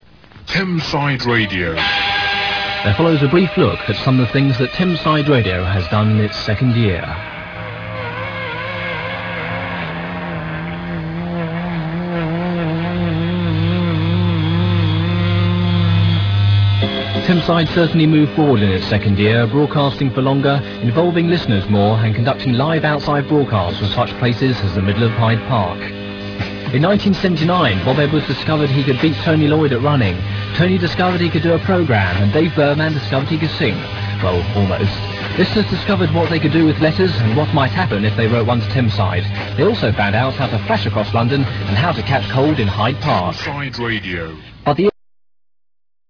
Here you can listen to off air and studio recordings of landbased pirate radio stations, they feature stations based in London and the home counties from the late 70's to almost the present day